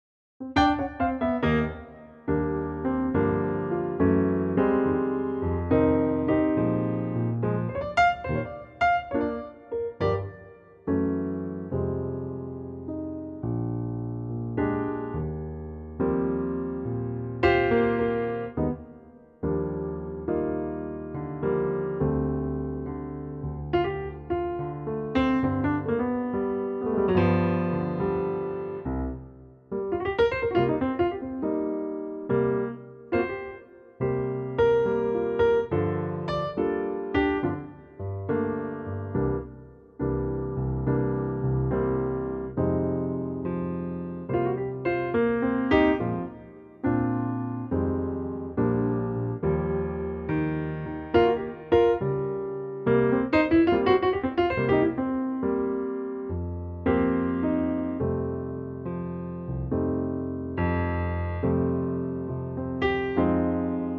key - Eb - vocal range - F to Ab
Superb piano only arrangement